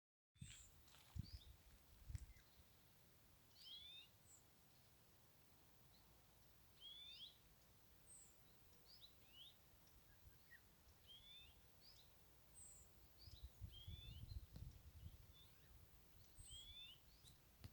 Birds -> Finches ->
Greenfinch, Chloris chloris
StatusPair observed in suitable nesting habitat in breeding season